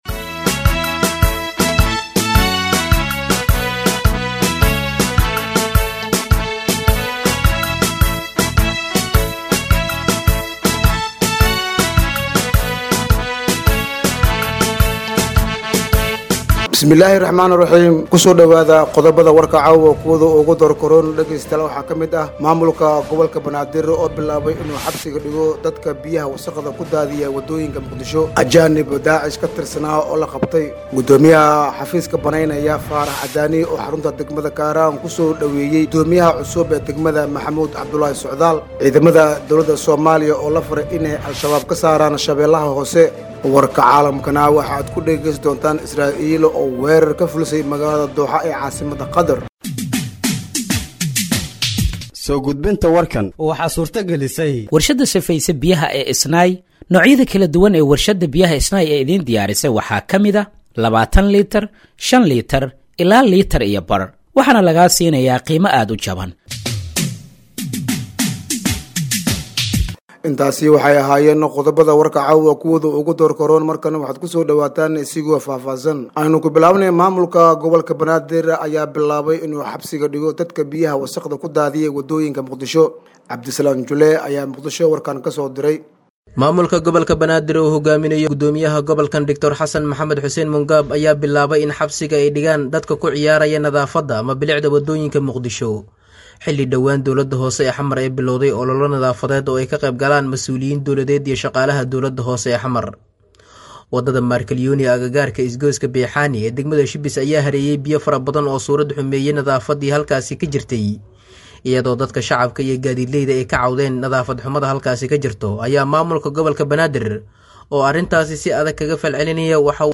Dhageeyso Warka Habeenimo ee Radiojowhar 09/09/2025